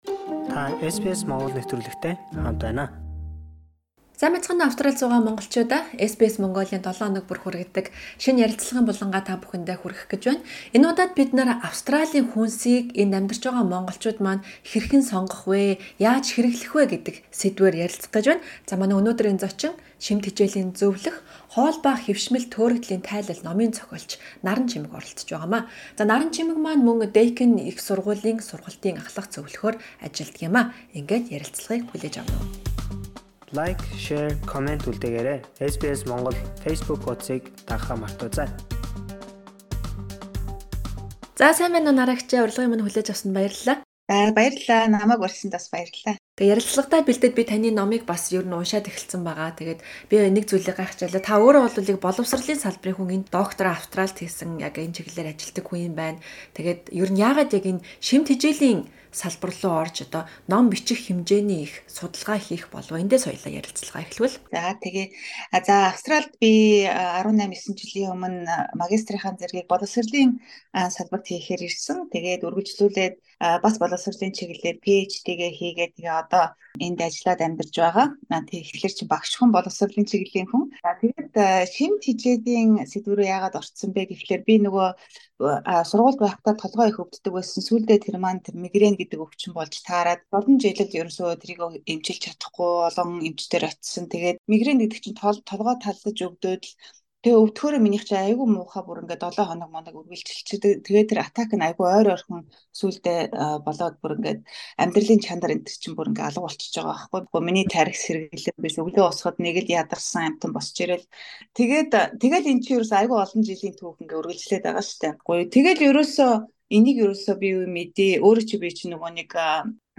Түүнтэй Австралид хэрхэн зөв, эрүүл хүнс сонгох талаар ярилцлаа.